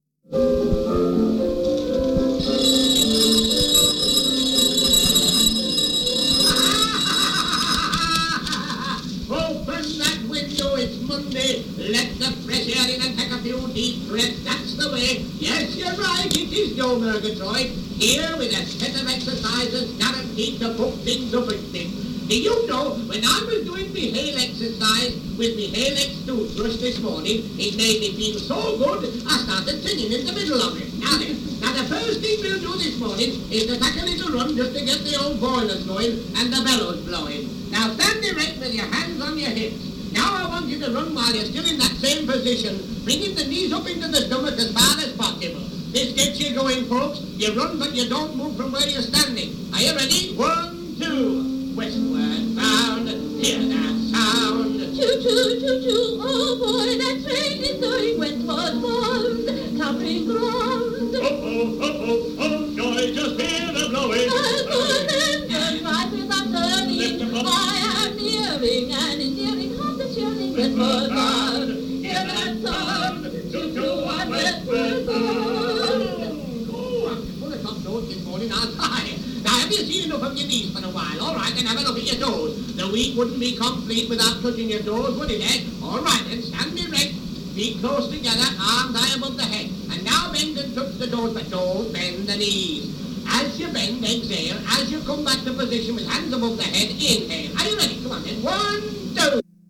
( Désolé pour la piètre qualité du son )